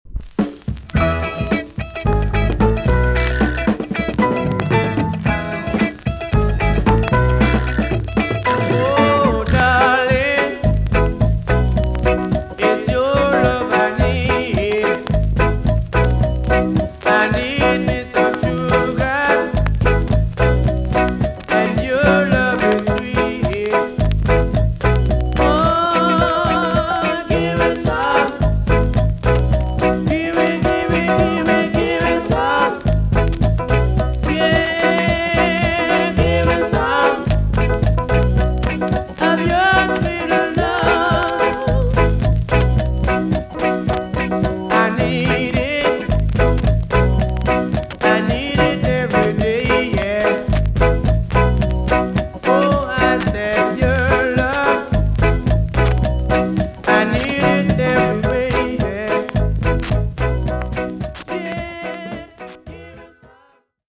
TOP >REGGAE & ROOTS
VG+~VG ok 部分的に軽いチリノイズがあります。
1975 , NICE VOCAL TUNE!!